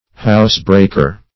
Housebreaker \House"break`er\, n.